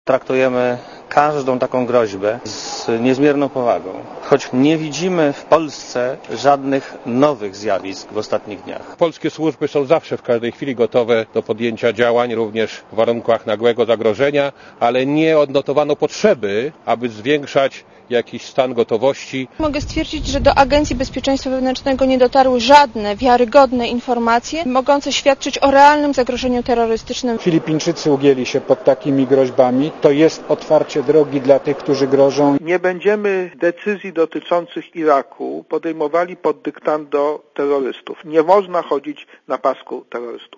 Posłuchaj komentarzy polskich polityków